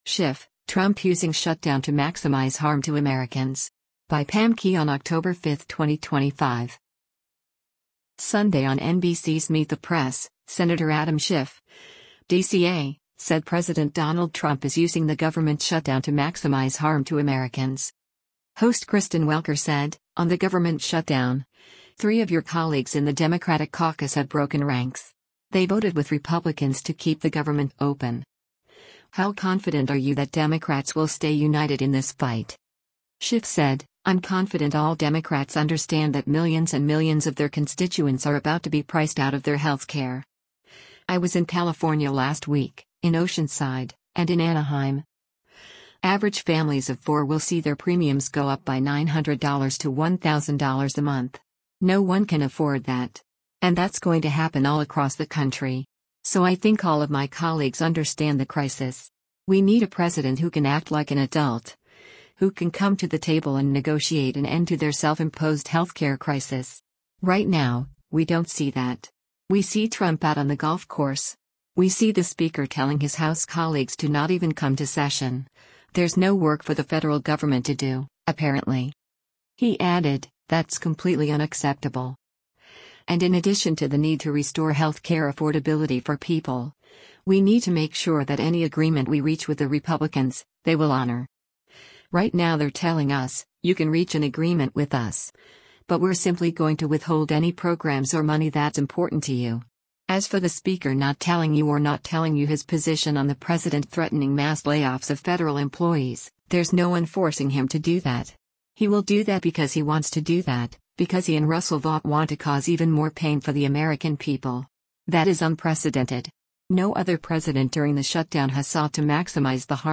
Sunday on NBC’s “Meet the Press,” Sen. Adam Schiff (D-CA) said President Donald Trump is using the government shutdown to maximize “harm” to Americans.